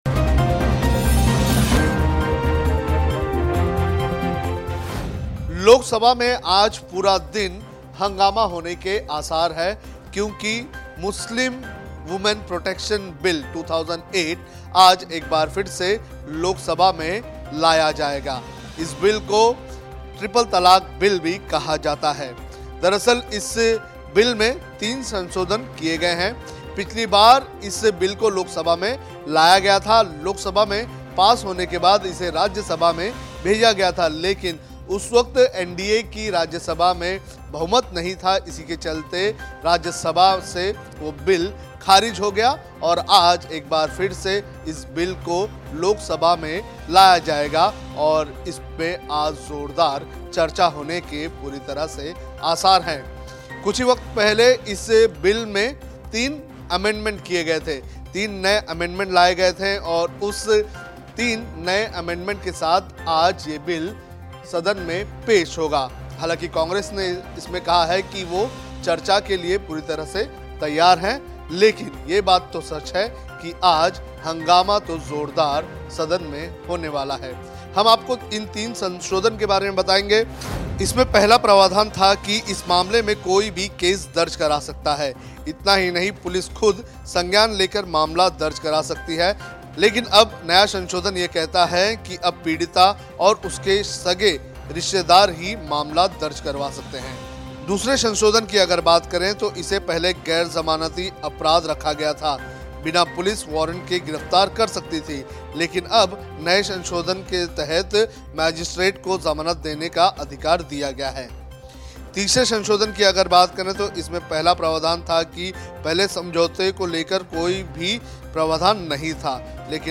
न्यूज़ रिपोर्ट - News Report Hindi / ट्रिपल तलाक़ बिल पर आज लोकसभा में चर्चा, तीन नए संशोधन के साथ लाया है बिल